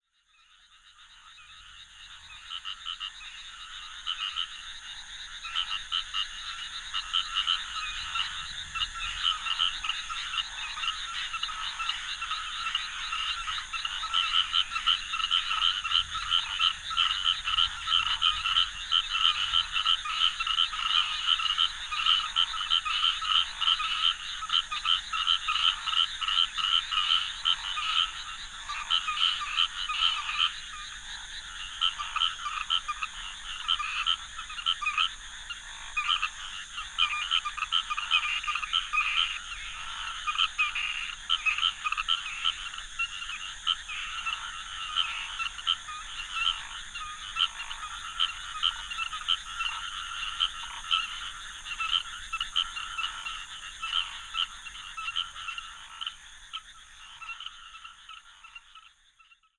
• ヌマガエルの声
ZOOM H6, RODE NT5 (Omni Stereo) 2016年7月17日　西日本某所
たぶん生涯はじめて聞くヌマガエルの合唱が印象深く、取材の手伝いを終えたその晩に録音して帰路につきました。